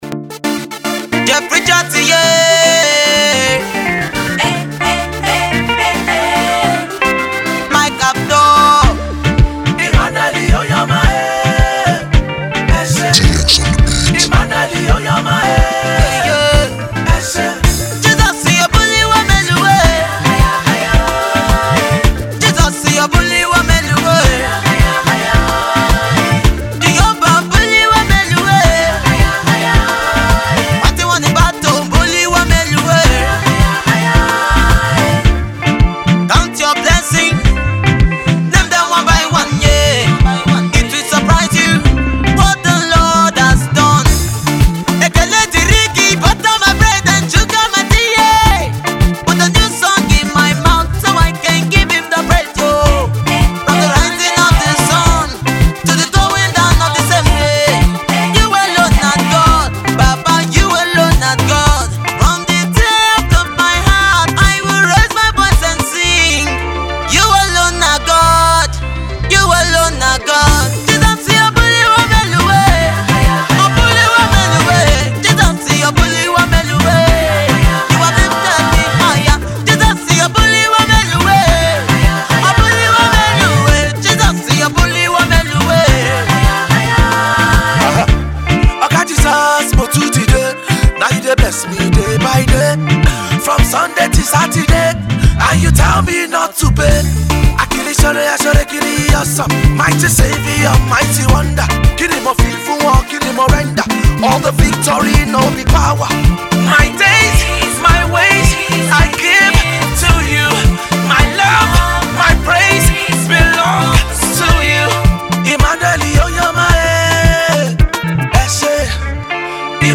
Gospel
spirit filled